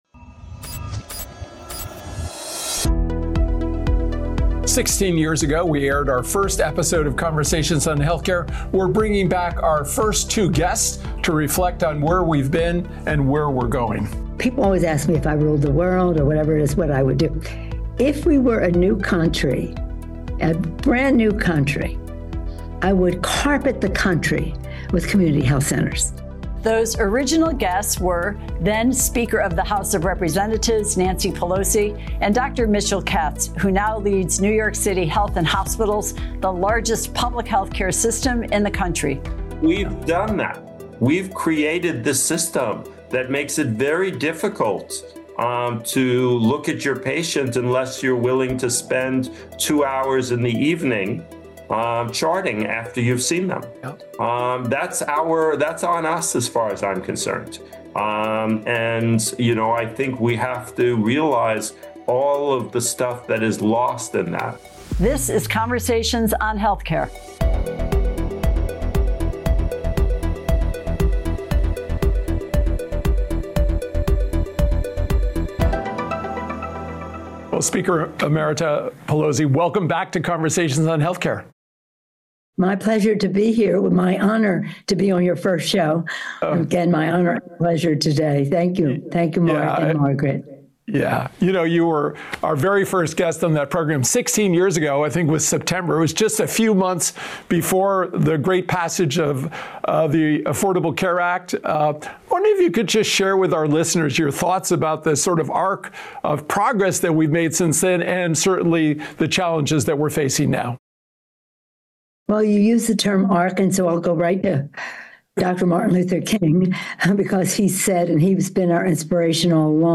Now, we’re bringing back our first two guests to reflect on where we’ve been and where we’re going in health care.